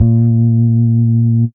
Wobble Bass Live (JW2).wav